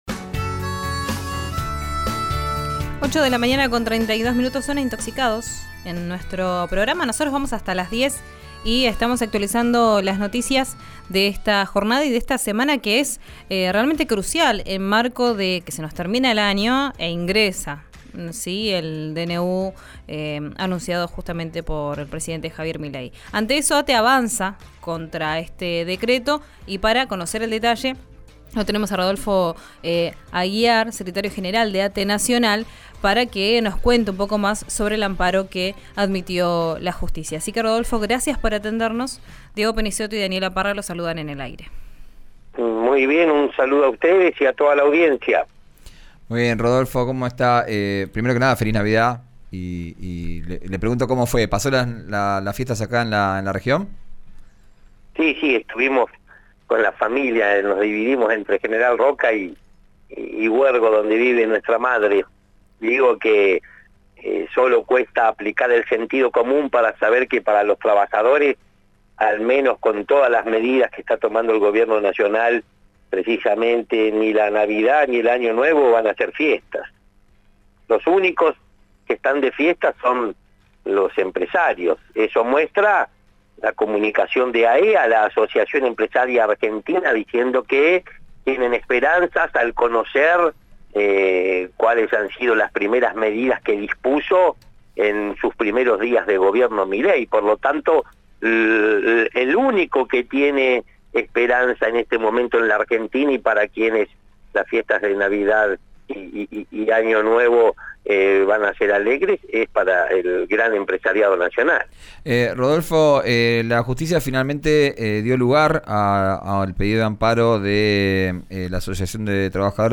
Además, dio detalles en una entrevista exclusiva con RÍO NEGRO RADIO.